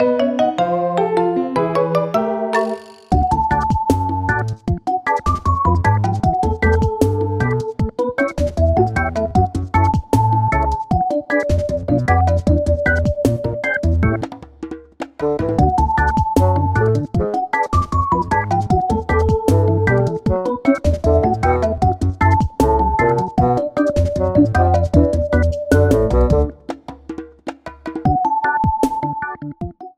The music is named and inspired after the reggae genre.